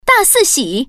Index of /mahjong_xianlai1/update/1311/res/sfx/common_woman/